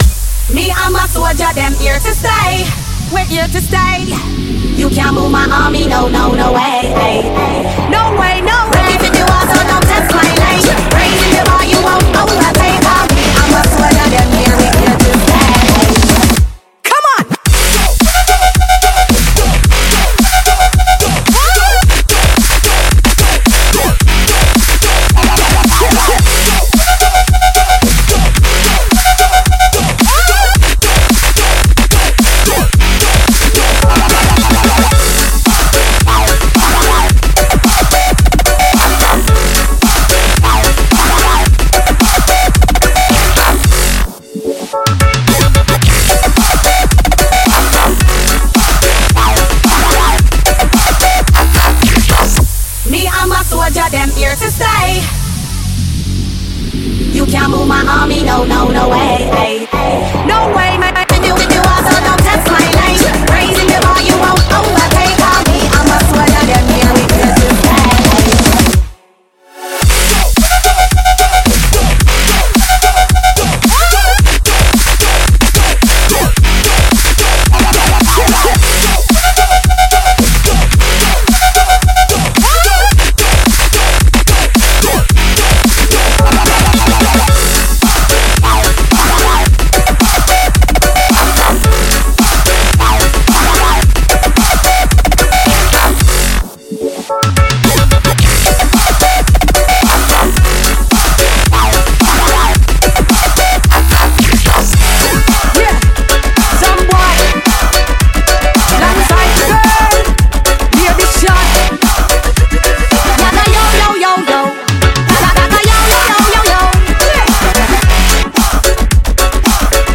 BPM110-110
Audio QualityPerfect (High Quality)
Edit 2020-07-21: Added genre string “Dubstep”.